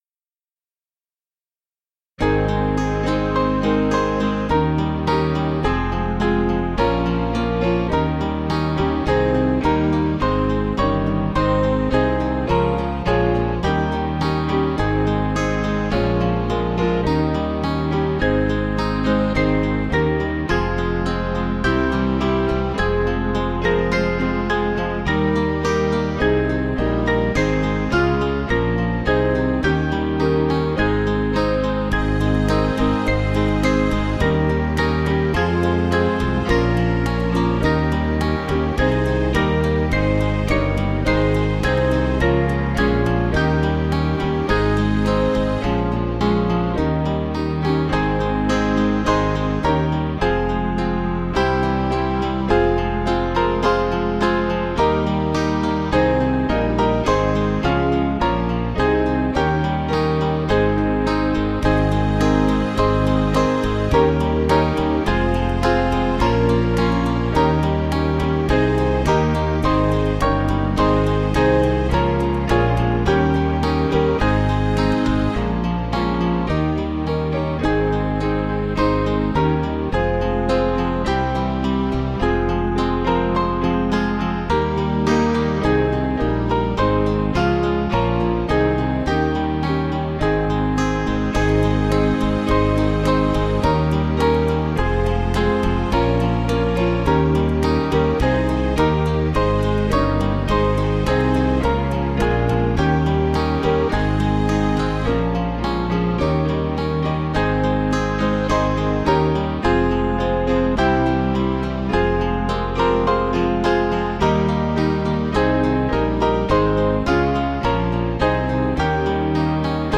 Small Band
(CM)   7/Ab